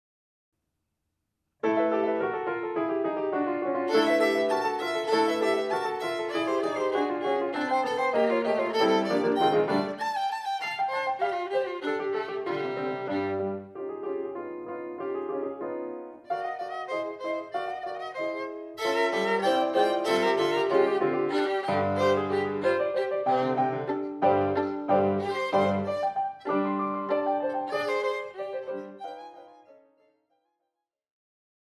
Besetzung Violine und Klavier
eingespielt von Sch�ler und Lehrer